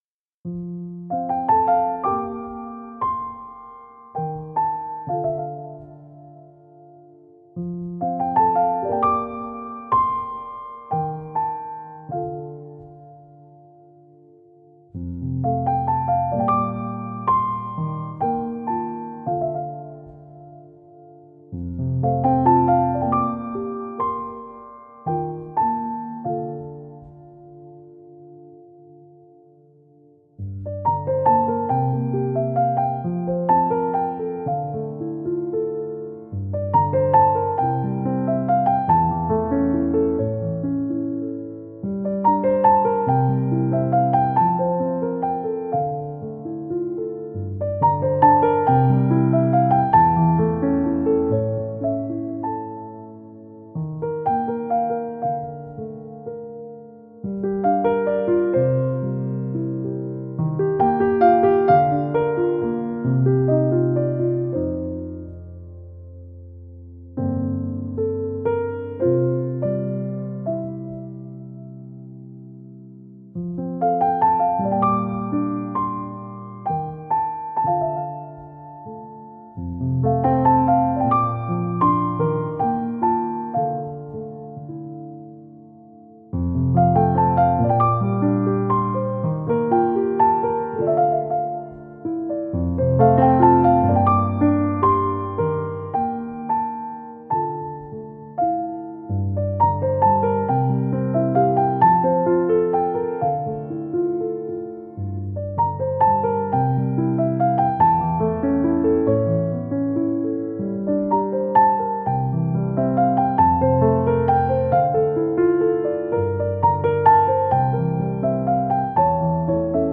۲. چیل (Chillout / Downtempo)